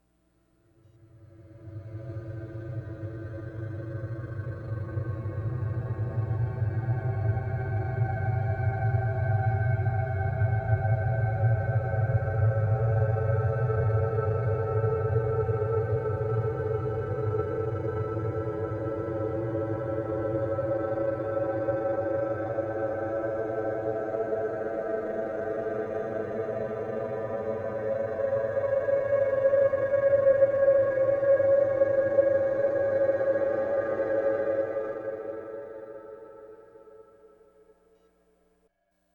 Source: Harmonic gliss on A (13:50-14:30) 1st part
Stereo Reduction